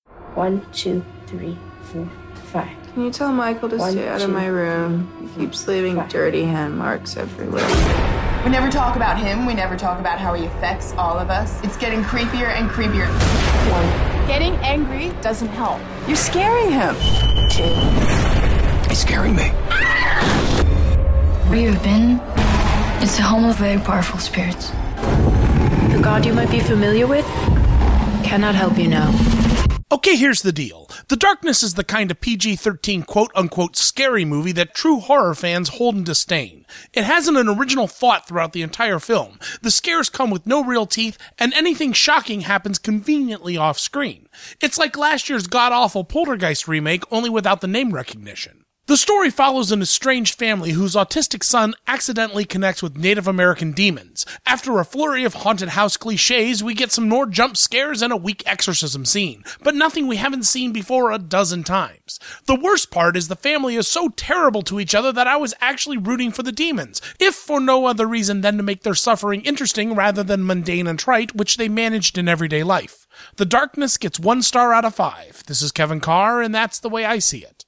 ‘The Darkness’ Radio Review